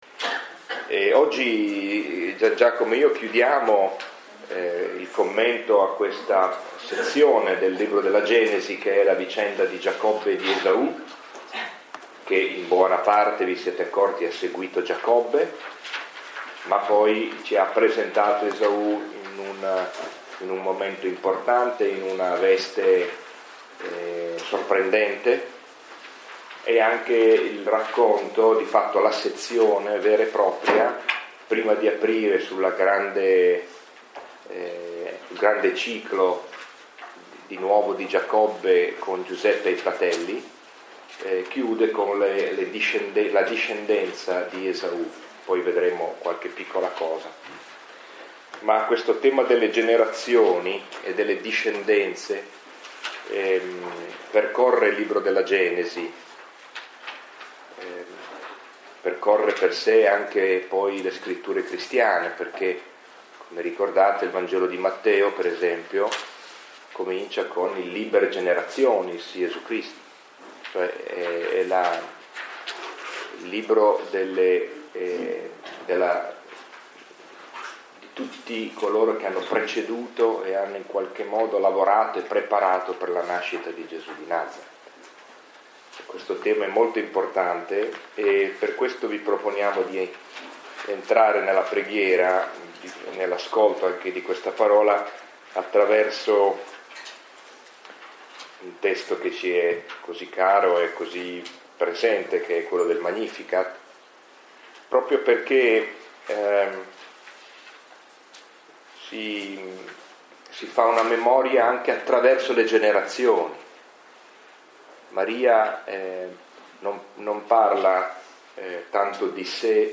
Lectio 7 – 19 aprile 2015 – Antonianum – Padova